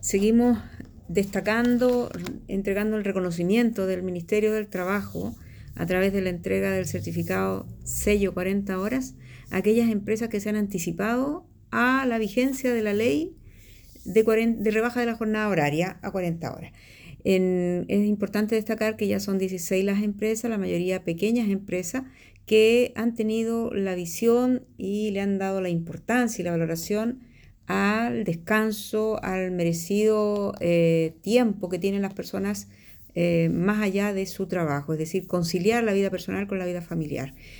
Al respecto la Seremi del Trabajo, Claudia Santander, comentó que